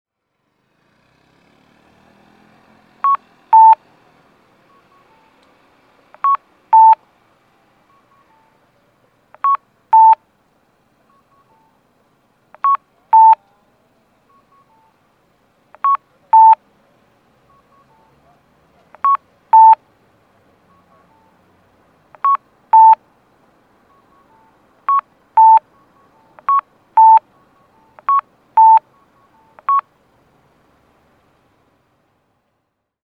交通信号オンライン｜音響信号を録る旅｜大分県の音響信号｜[別府:0249]郵政健康管理センター前
郵政健康管理センター前(大分県別府市)の音響信号を紹介しています。